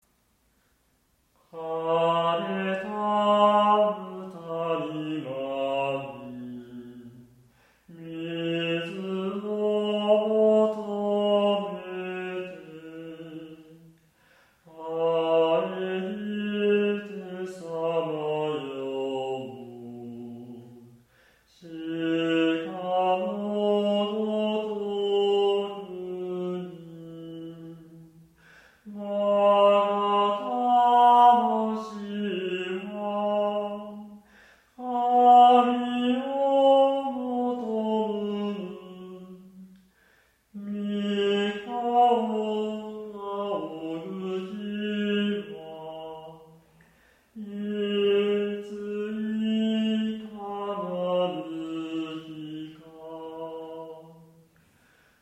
この歌唱では完全なピタゴラス音階ではなく下降音形にミーントーンを
録音では１行目に勢いあまって外し、２行目以降は目的を達しています。